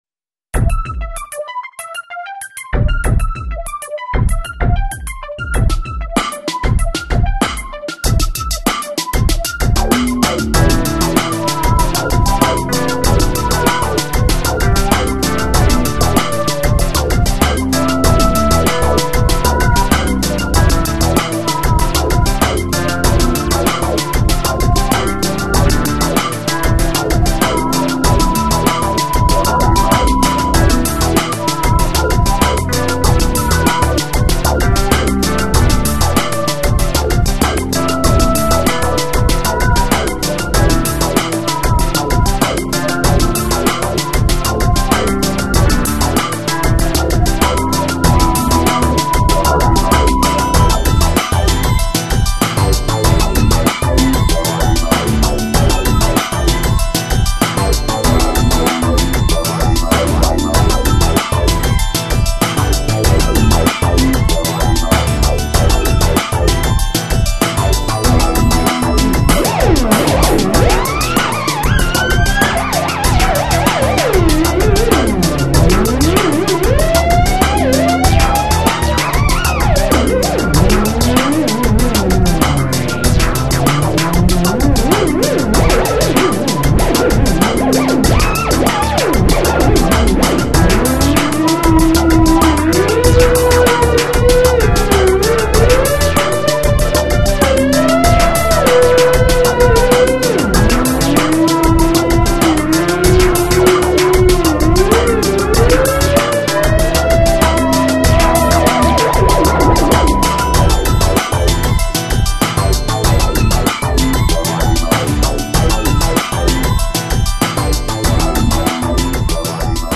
アンビエント / テクノをテーマとしたオリジナル曲集。
MPEG 1.0 layer 3 / 96kbit / stereo